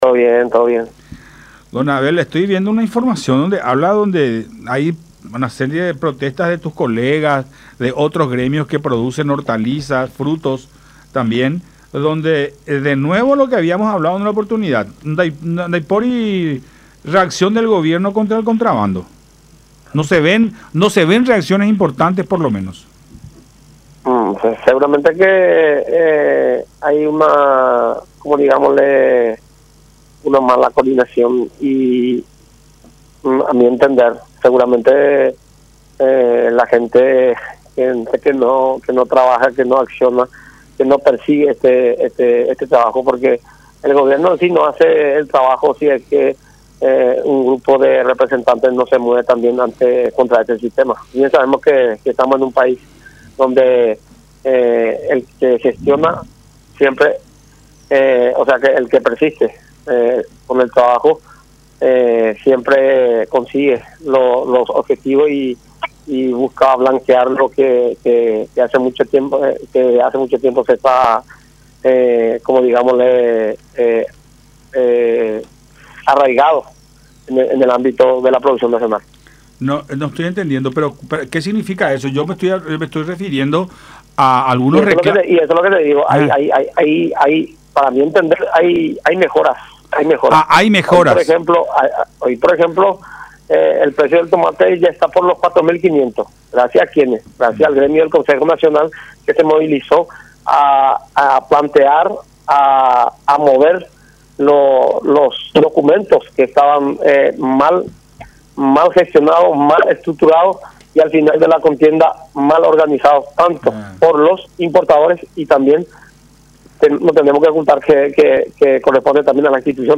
en diálogo con Nuestra Mañana a través de Unión TV y radio La Unión